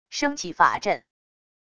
升起法阵――wav音频